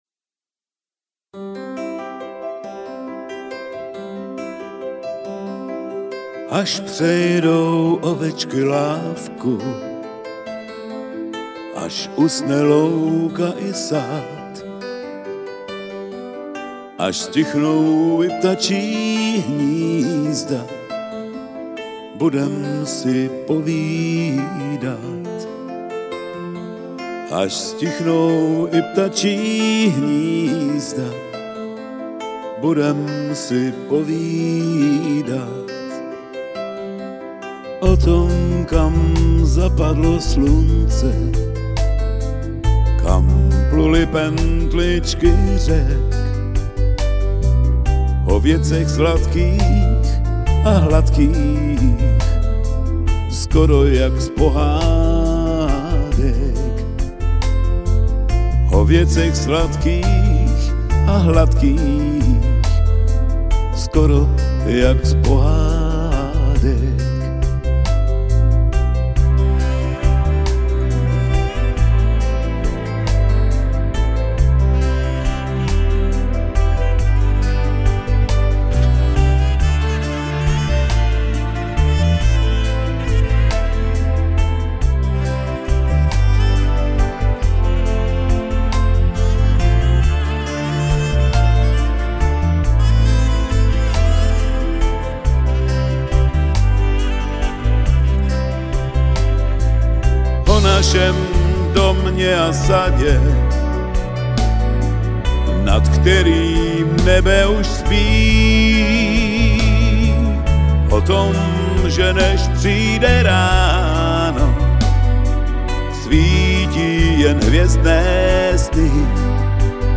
Ovečky z Frankfurtu - demo nahrávka